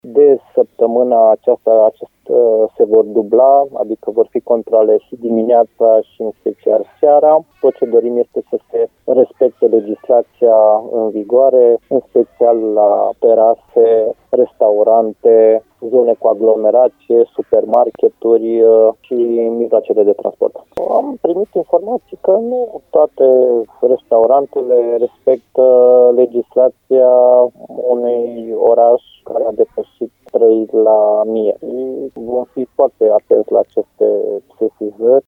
Directorul Direcției de Sănătate Publică, Horea Timiș: